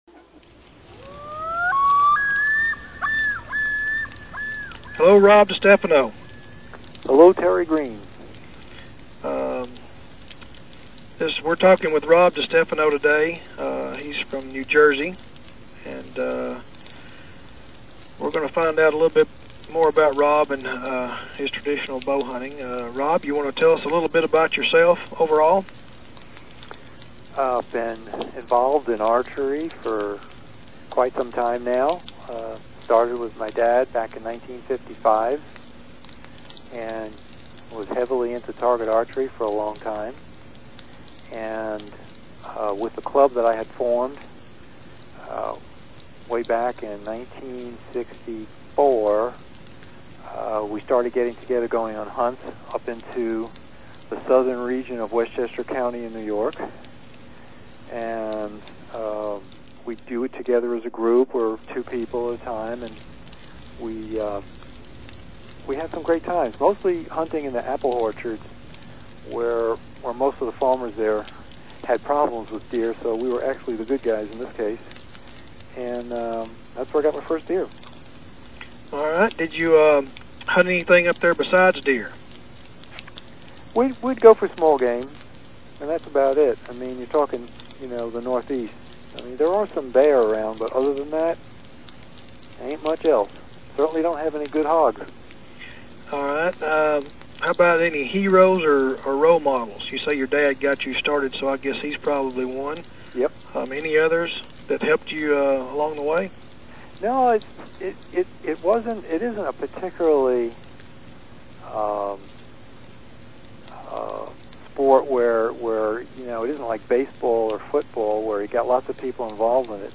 I thought I heard a cricket.